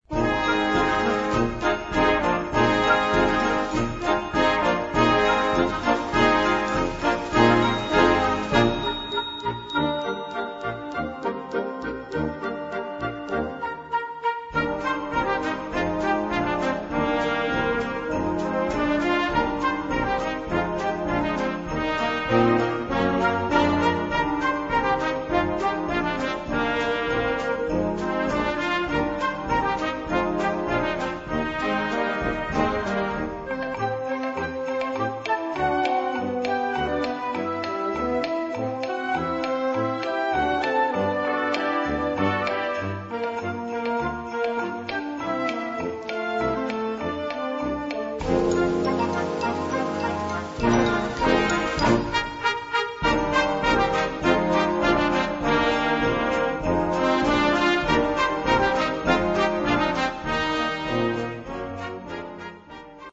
Gattung: Weihnachten
Besetzung: Blasorchester
This is a meaningful holiday work arranged for your band.